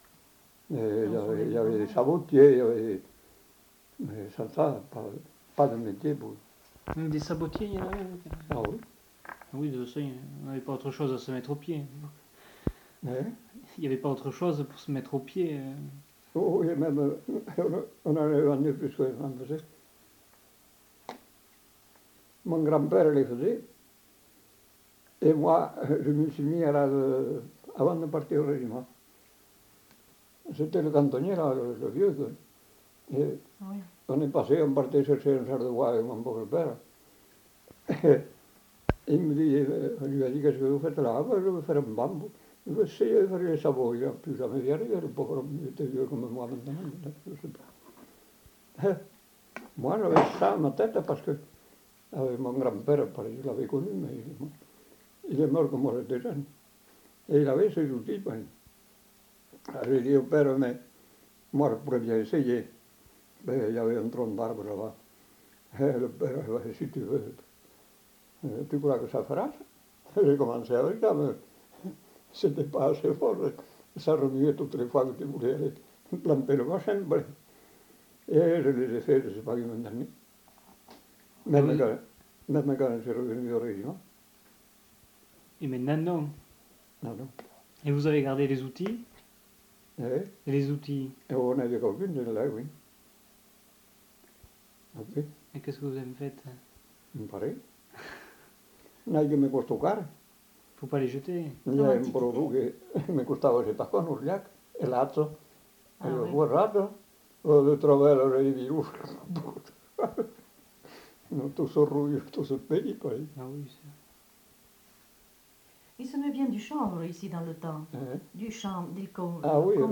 Lieu : Lacroix-Barrez
Genre : témoignage thématique